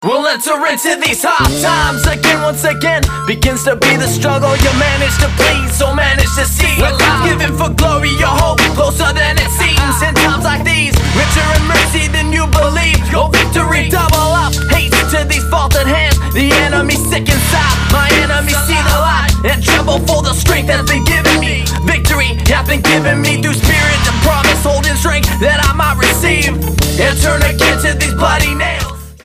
STYLE: Pop
Fine singer, fine band, fine songs.
melodic pop/rock